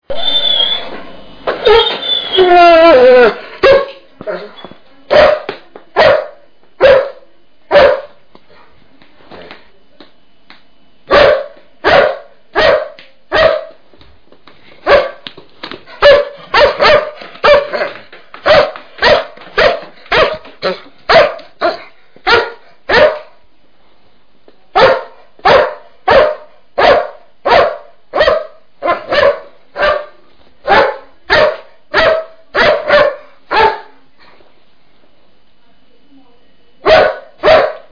Ladrido1 Téléchargement d'Effet Sonore